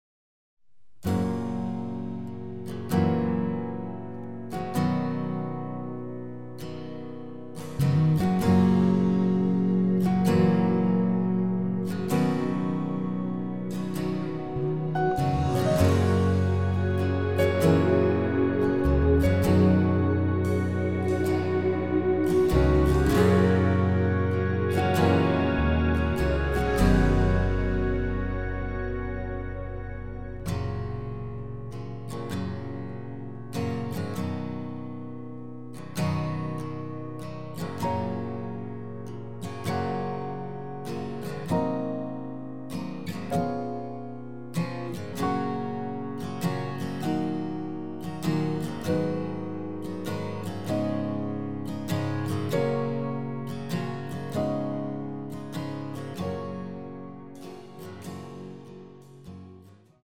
[공식 음원 MR]
F#
앞부분30초, 뒷부분30초씩 편집해서 올려 드리고 있습니다.